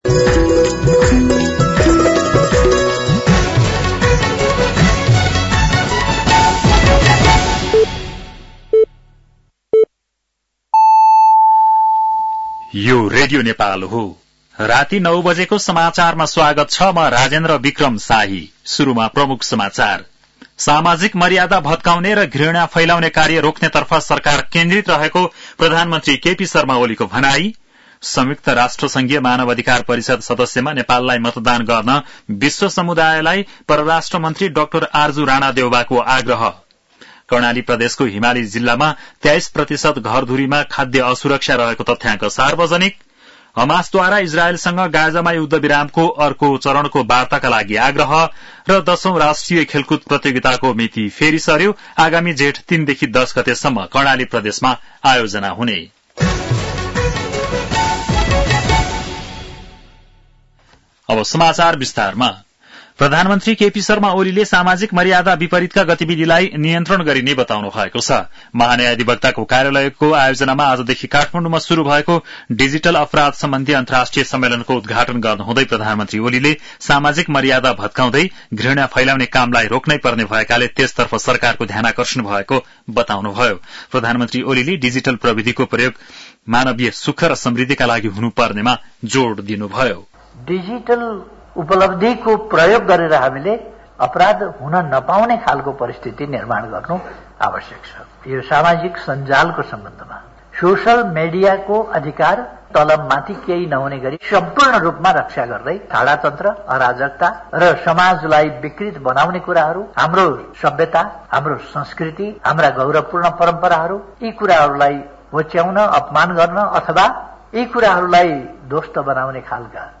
बेलुकी ९ बजेको नेपाली समाचार : १६ फागुन , २०८१
9-PM-Nepali-NEWS-11-15.mp3